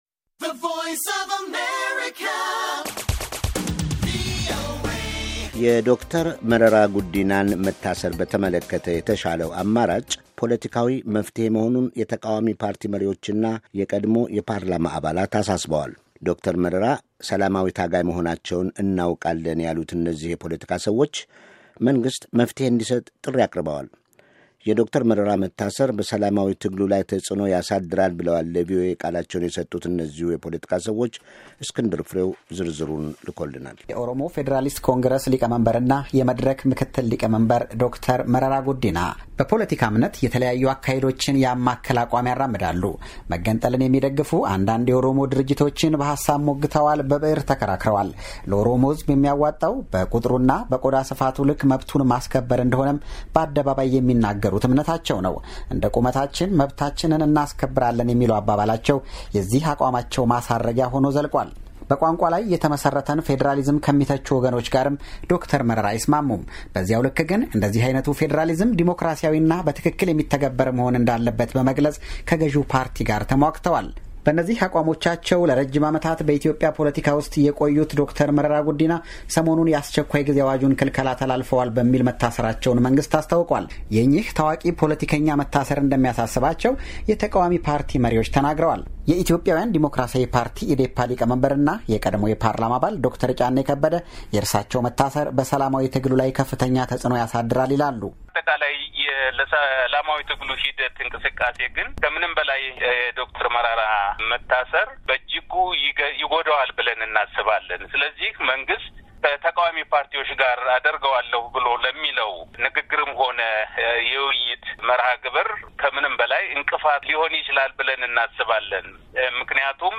“የዶ/ር መረራ መታሰር በሠላማዊ ትግሉ ላይ ተፅዕኖ ያሳድራል” ብለዋል ለቪኦኤ ቃላቸውን የሠጡት እነዚሁ የፖለቲካ ሠዎች፡፡